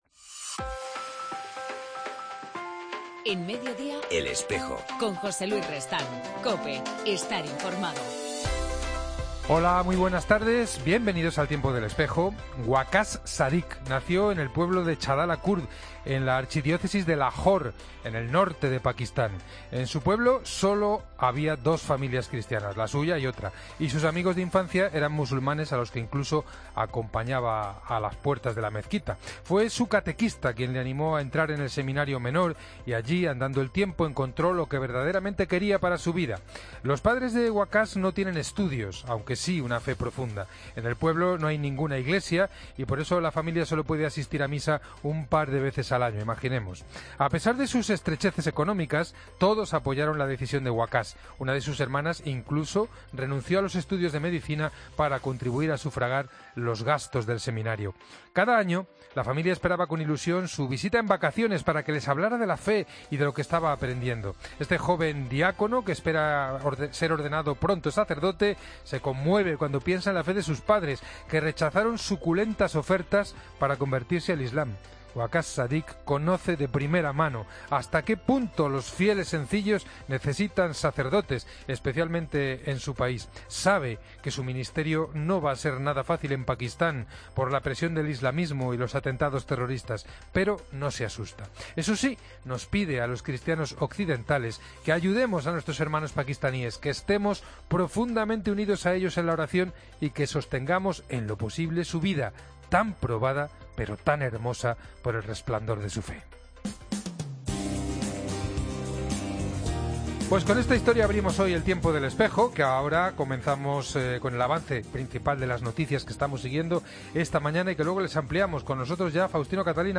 AUDIO: En El Espejo del 29 de diciembre hablamos con Mons. Juan José Aguirre, obispo de Bangassou, en la República Centroafricana.